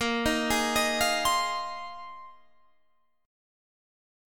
Bb9sus4 chord